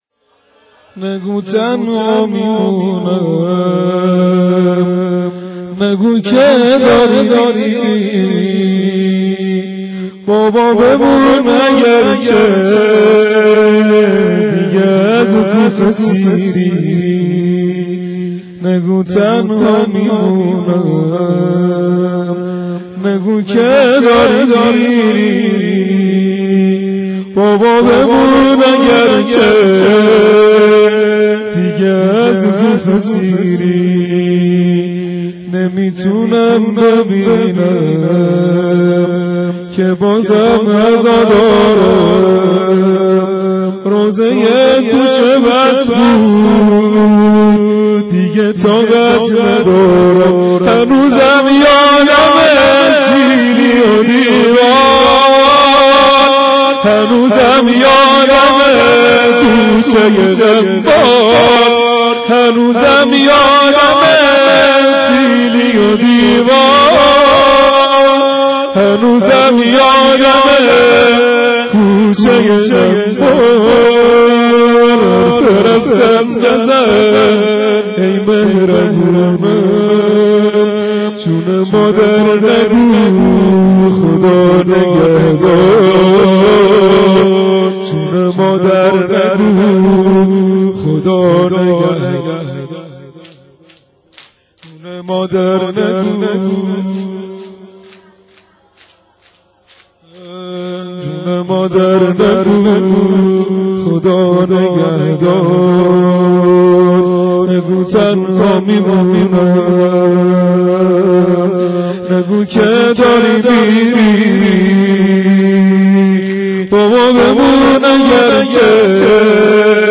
شب 21 رمضان 92